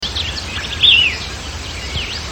Fiofío Pico Corto (Elaenia parvirostris)
Correspondiente a la parte escuchada del canto.
Fase de la vida: Adulto
Localización detallada: Rio Medinas
Condición: Silvestre
Certeza: Fotografiada, Vocalización Grabada